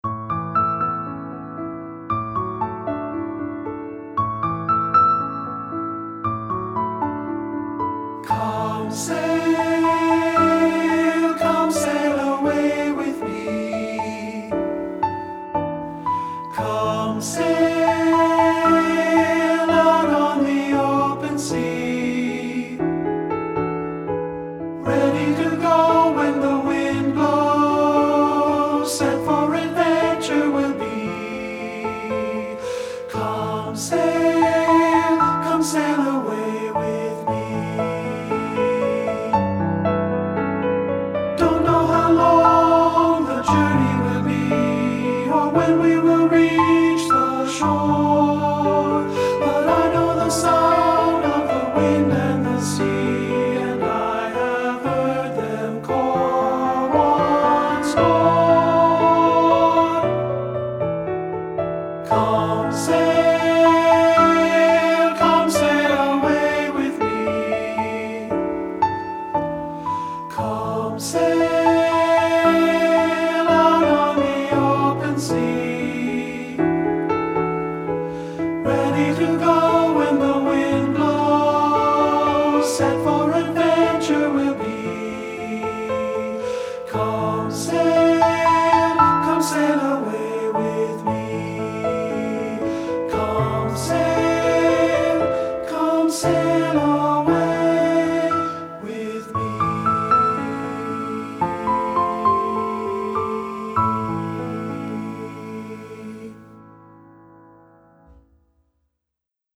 Unison/Optional Two-Part Unchanged Voices with Piano
• Piano
Ensemble: Tenor-Bass Chorus
Accompanied: Accompanied Chorus